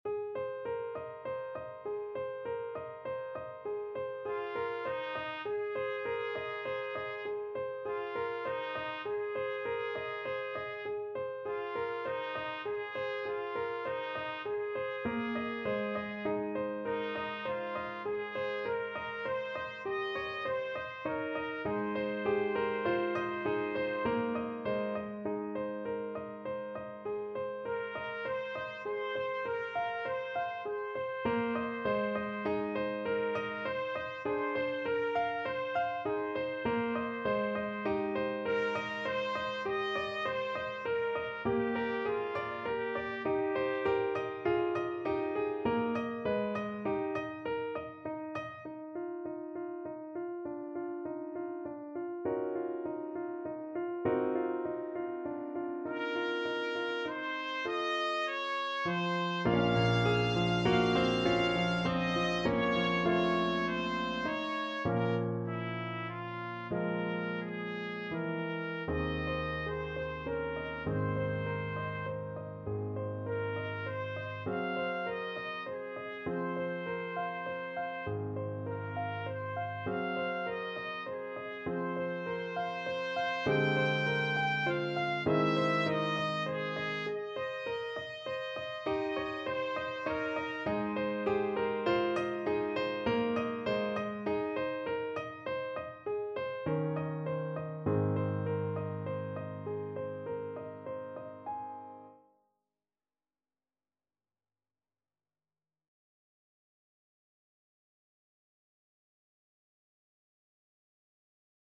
Trumpet
~ = 120 Allegretto
Ab major (Sounding Pitch) Bb major (Trumpet in Bb) (View more Ab major Music for Trumpet )
9/4 (View more 9/4 Music)
Classical (View more Classical Trumpet Music)